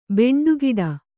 ♪ beṇḍu giḍa